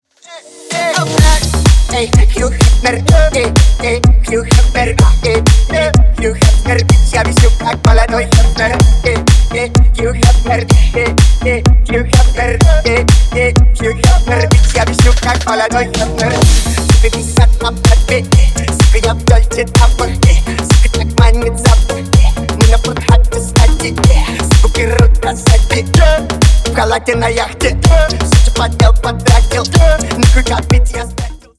Ремикс # Рэп и Хип Хоп